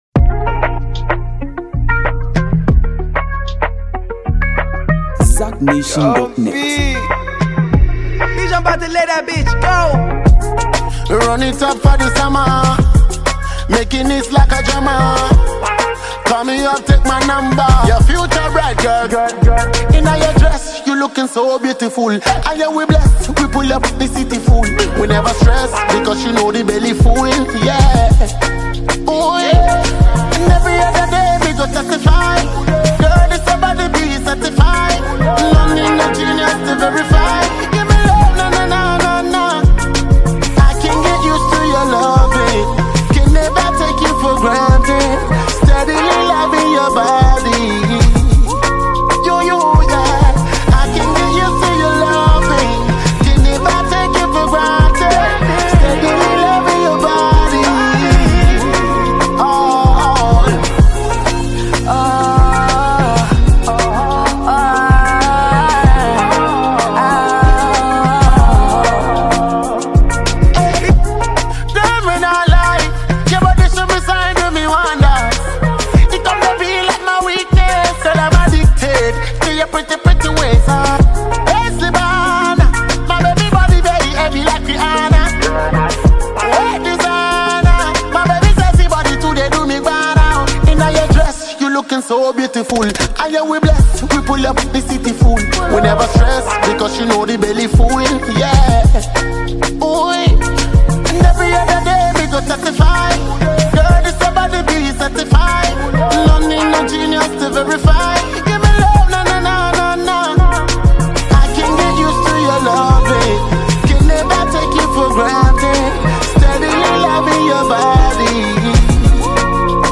the acclaimed Ghanaian reggae and dancehall artist
infectious beats